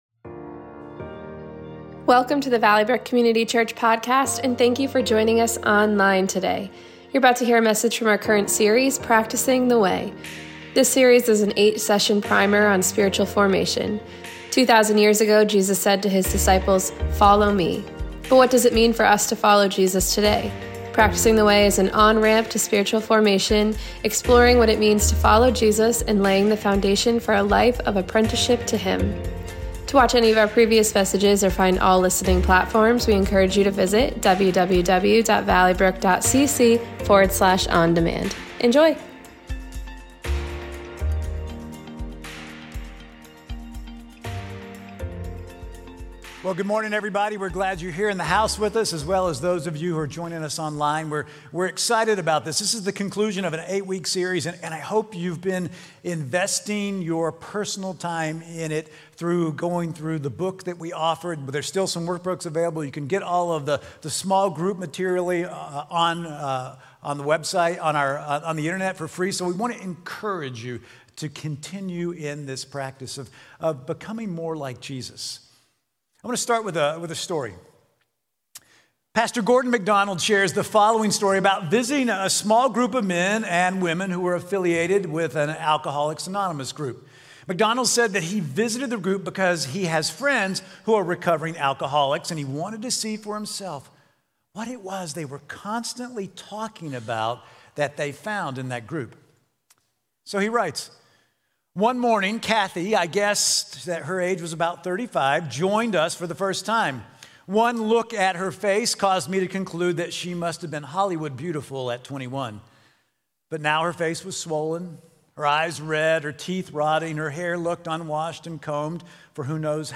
Valley Brook Sermon podcast